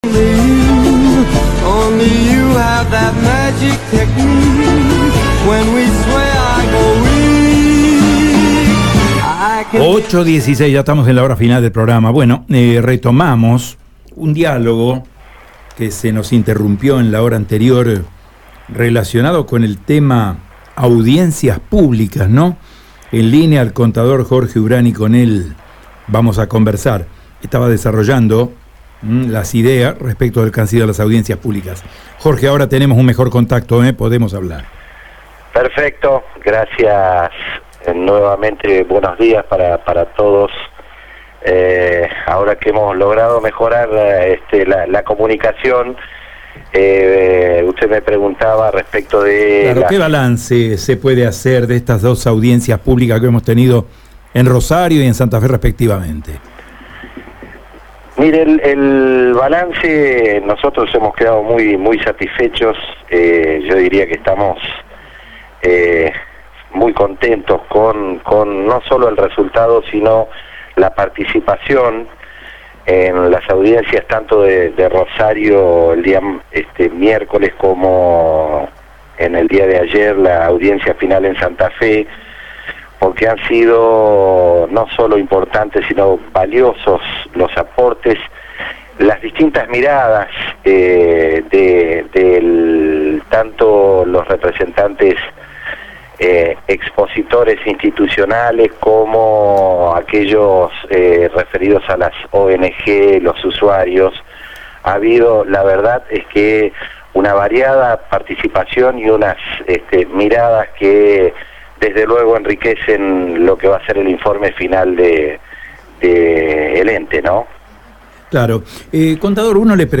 Por otro lado, entrevistado detalló que «Para contextualizar, no solo la empresa debe afrontar costos, estamos hablando del área servida, el 70% de los usuarios paga, en promedio, $1.300. En términos de cuanto sale una gaseosa, la tarifa está muy retrasada.